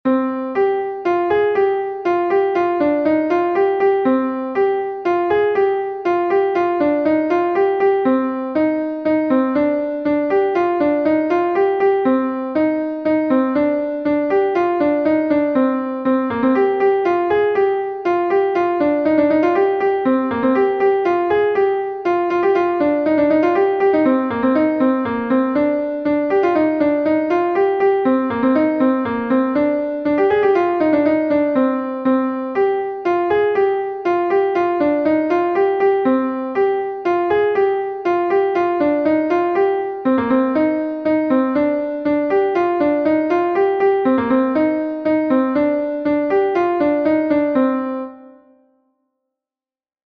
Laridenn Henbont est un Laridé de Bretagne